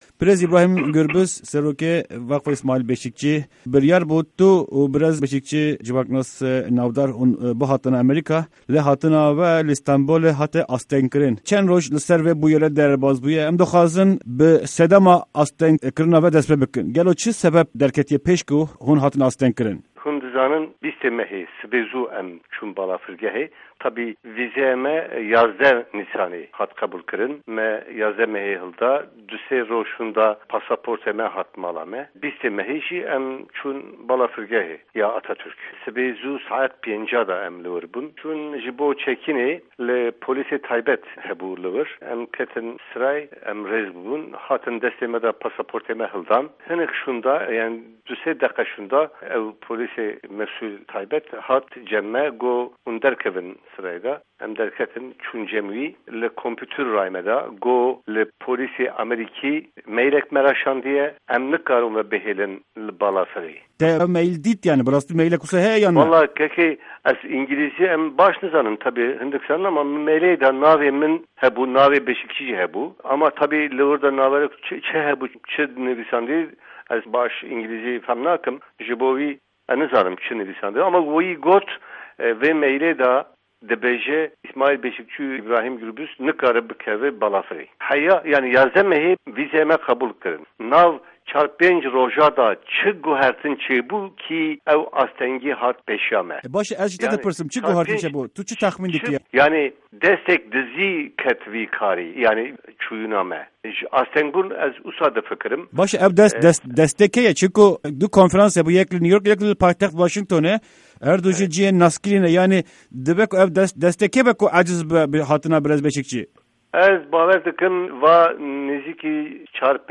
Di hevpeyvîna Dengê Amerîka de